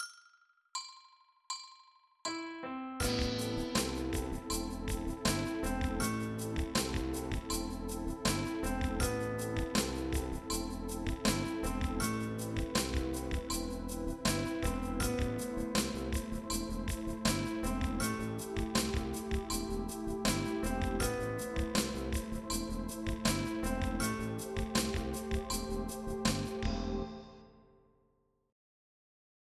Dołożymy po dwa dźwięki, które będą poprzedzać nuty "kluczowe" - budujące i rozwiązujące tension (B, A).
W efekcie poprzedzania, frazę musimy zacząć z "przedtaktu", czyli przed "raz".